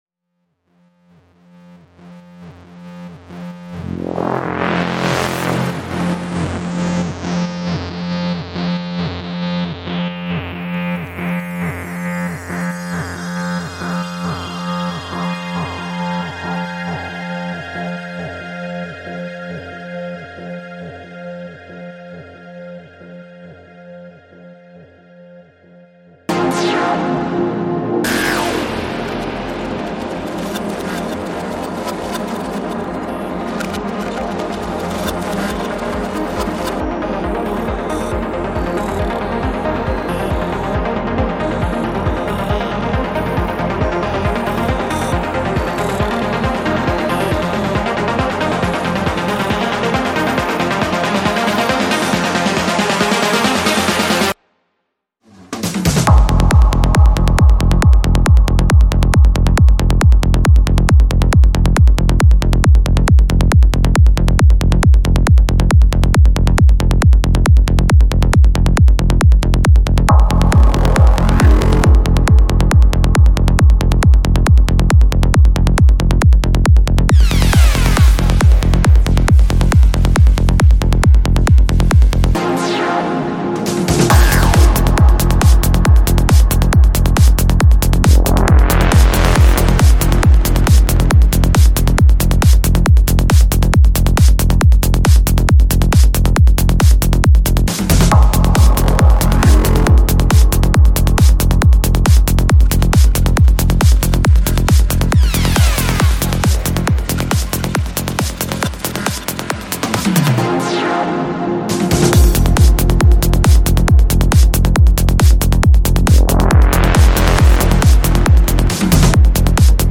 Жанр: Trance
Альбом: Psy-Trance